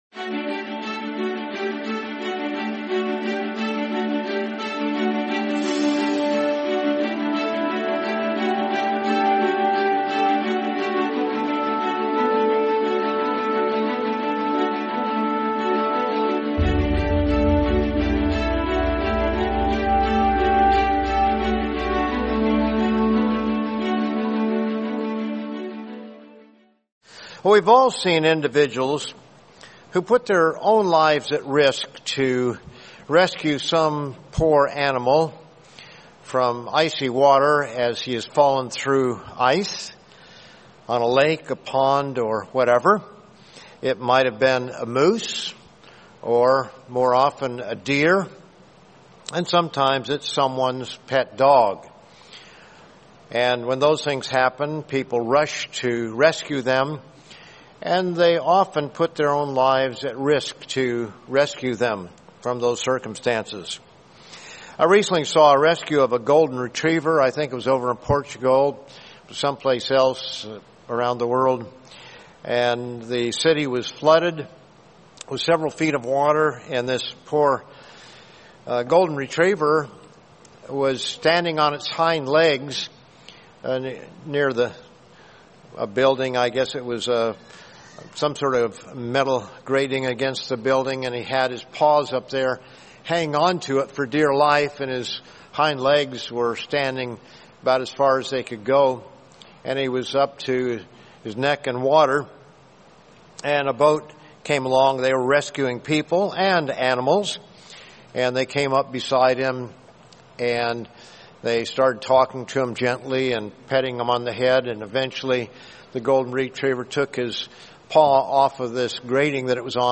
Courtesy and Kindness | Sermon | LCG Members